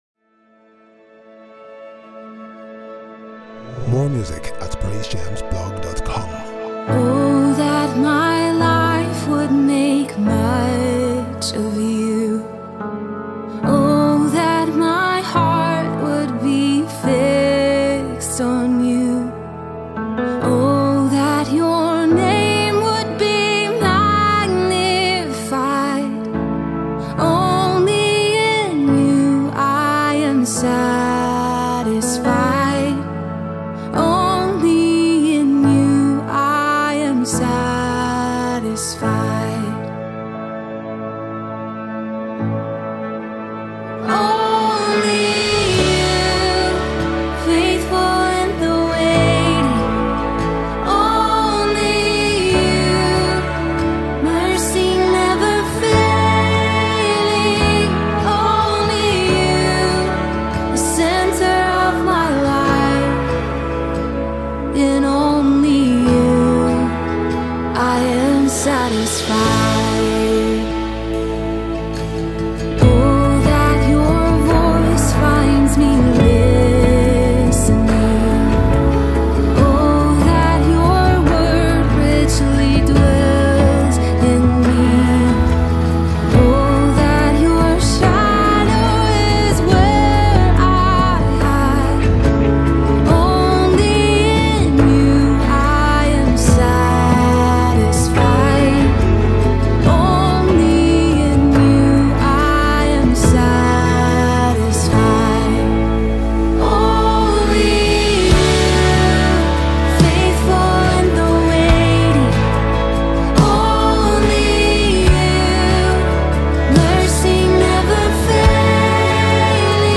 worship sound